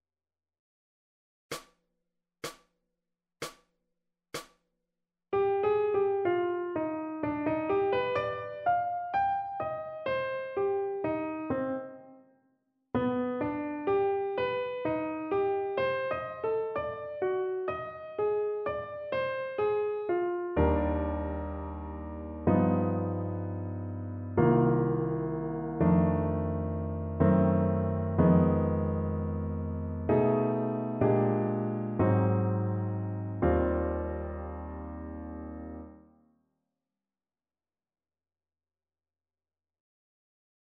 Jazz i música moderna
Ab-dictat-harmonic-jazz-moderna-audio-24-06.mp3